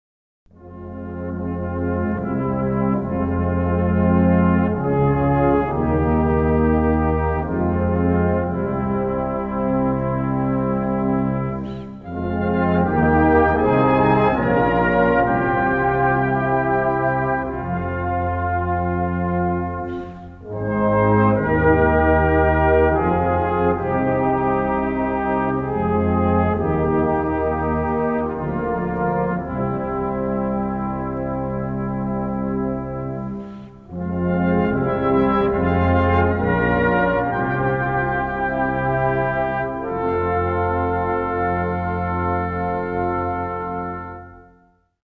Category Concert/wind/brass band
Instrumentation Ha (concert/wind band)